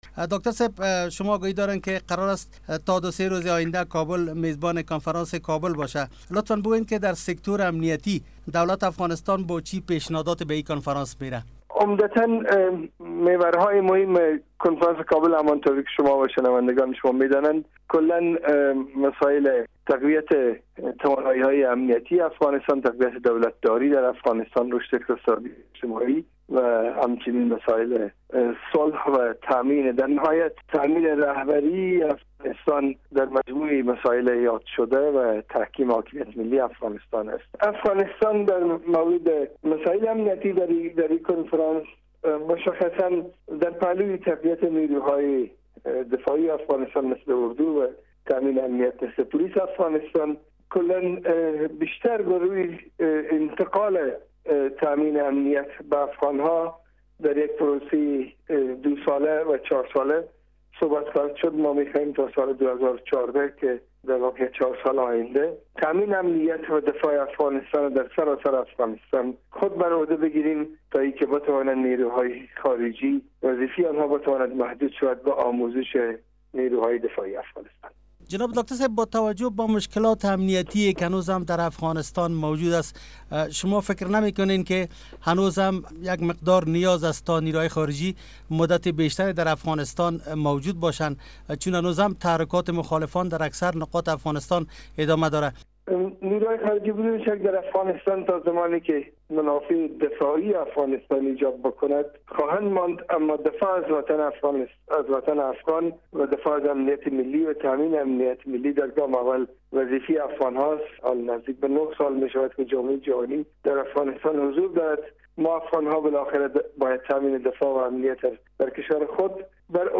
مصاحبه با داکتر رنگین دادفر سپنتا در مورد ارایهء طرح های امنیتی به کنفرانس کابل